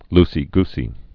(lsē-gsē)